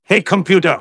synthetic-wakewords
ovos-tts-plugin-deepponies_Spy_en.wav